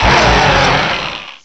cry_not_excadrill.aif